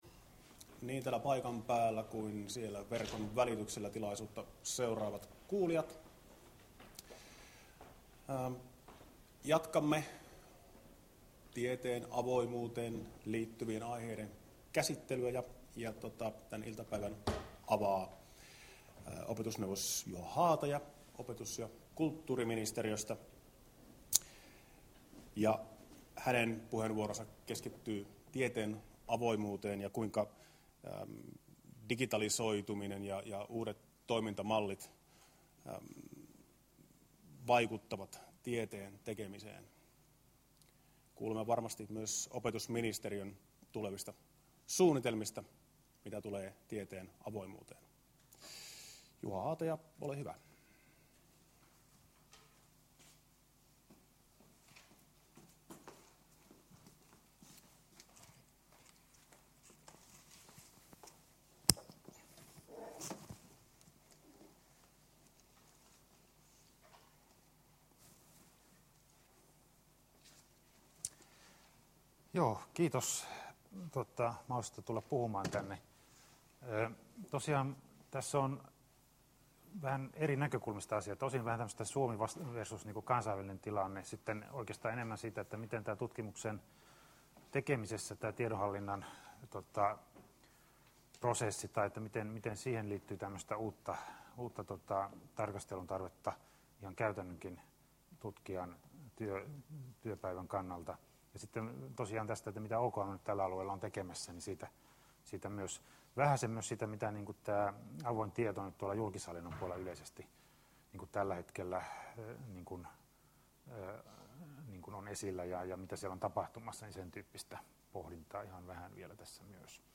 Tieteen julkisuus seminaarissa 12.4.2013 keskustellaan ajankohtaisista tieteelliseen julkaisutoimintaan liittyvistä kysymyksistä.